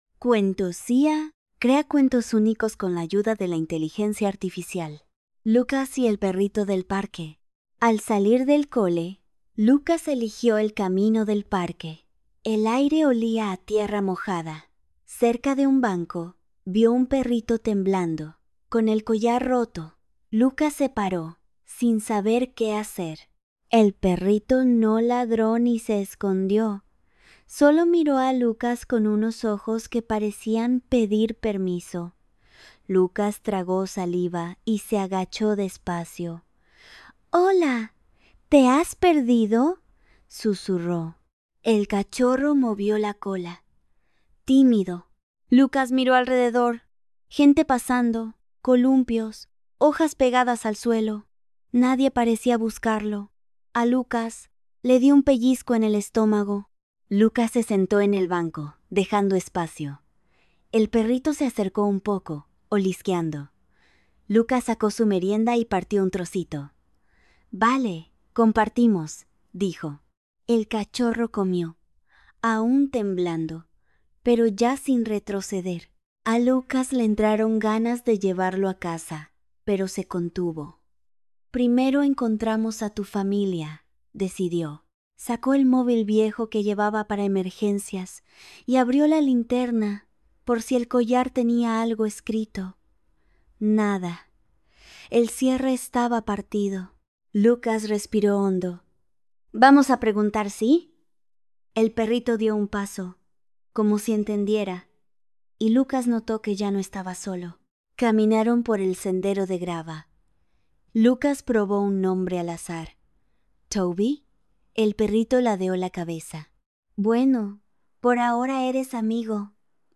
Audiolibro Narrado